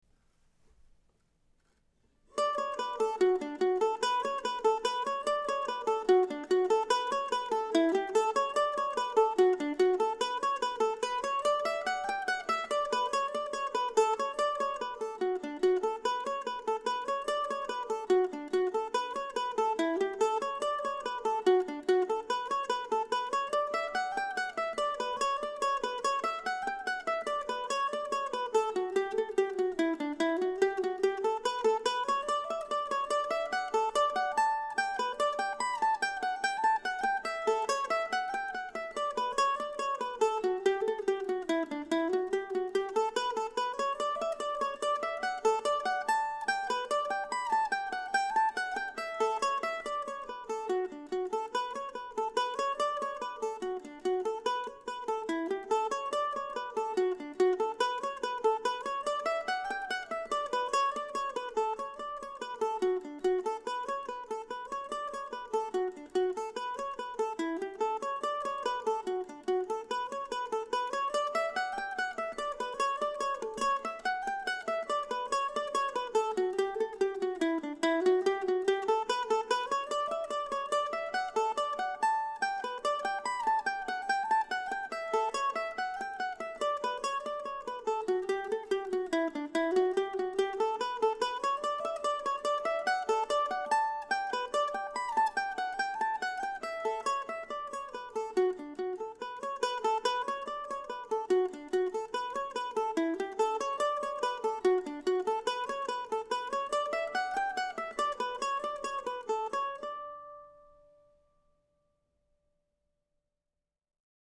I usually play it in D but you can transpose it to other keys for fun.